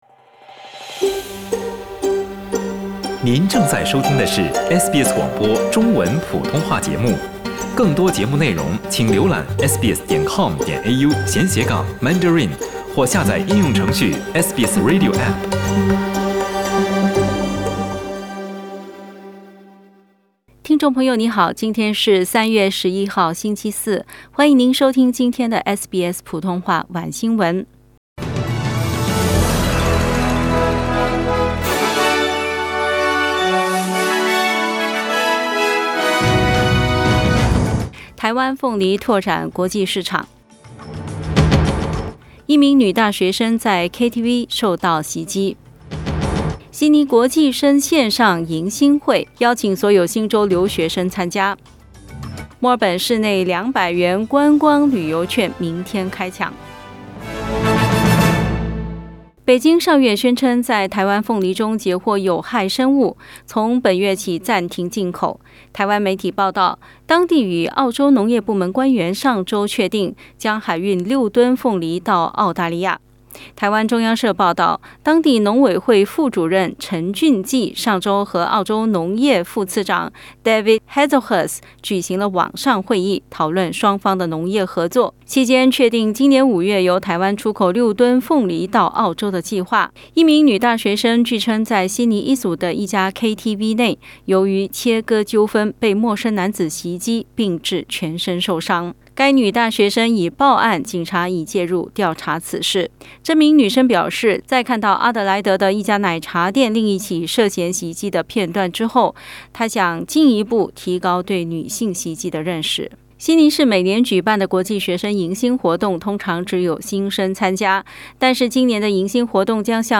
SBS晚新聞（3月11日）